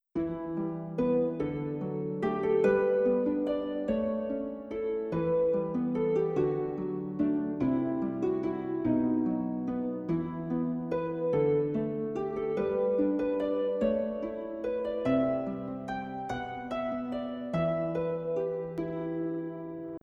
Market.wav